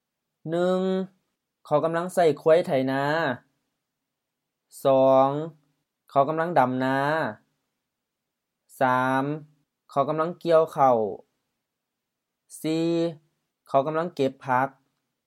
เขา khao M เขา personal pronoun: he, she
กำลัง gam-laŋ M-HR กำลัง auxiliary indicating continuous or progressive action